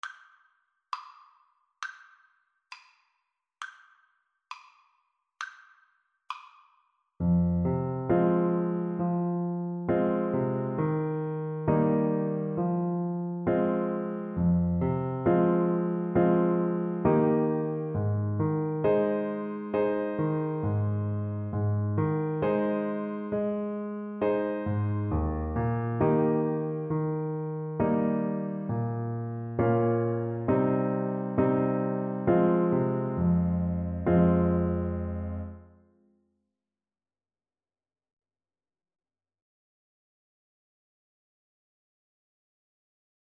2/4 (View more 2/4 Music)
Moderato